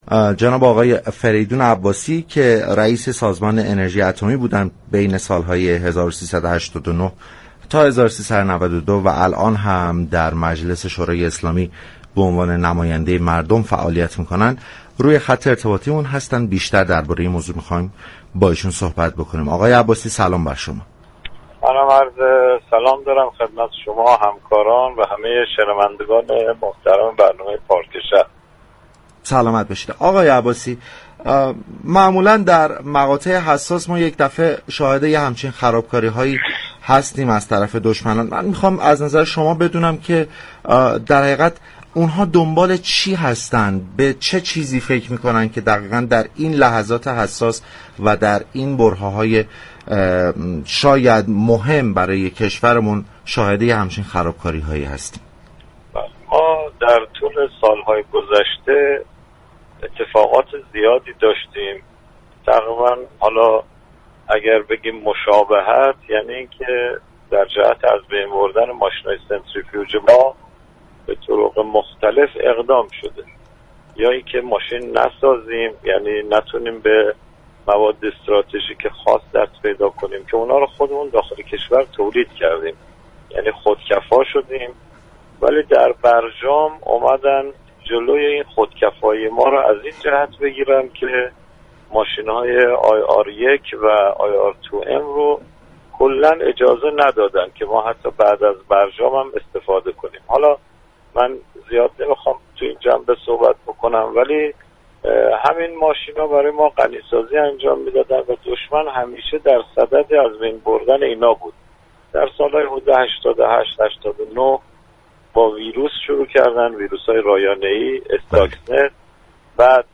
در همین راستا برنامه پارك شهر 24 فروردین با فریدون عباسی رئیس كمیسیون انرژی مجلس یازدهم و رئیس اسبق سازمان انرژی اتمی درباره این خرابكاری و چرایی انجام آن گفتگو كرده است.